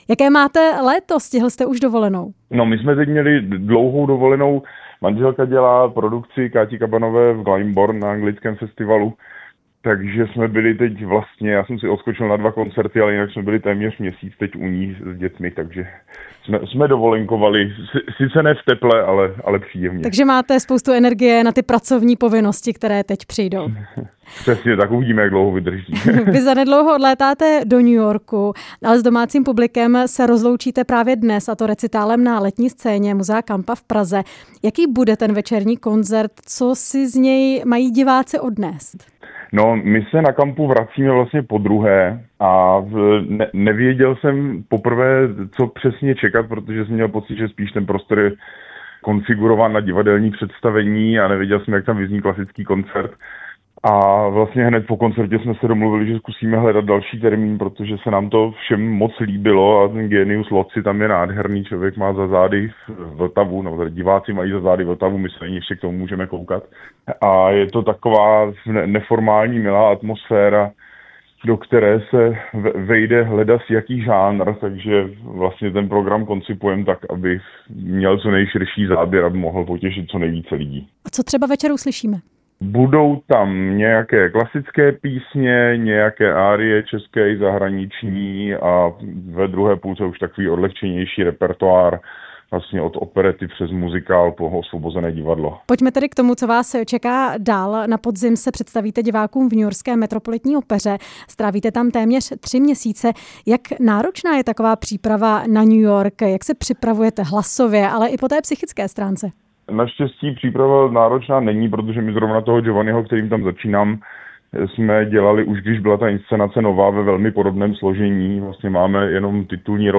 Basbarytonista Adam Plachetka byl hostem ve vysílání Rádia Prostor.
Rozhovor s basbarytonistou Adamem Plachetkou